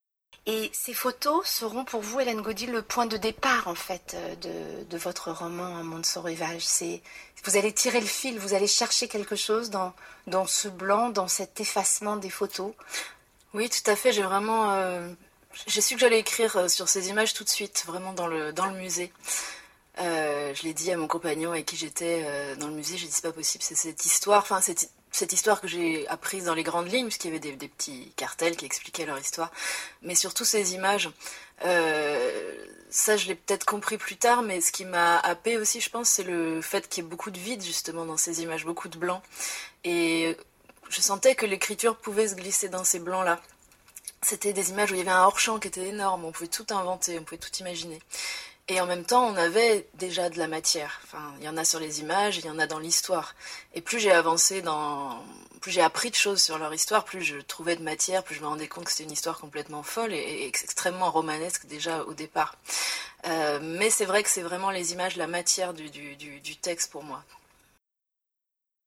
Et après, vous devriez écouter cette émission qui avait pour invitée l’auteure de ce livre en septembre dernier.